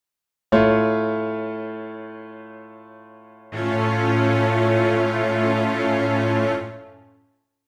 An Augmented Chord contains a Major Third (four half-steps) from the root to the third and a Major Third (four half-steps) from the third to the fifth. a_augmented_chord
Click to hear an A+ chord.
a_augmented_chord.mp3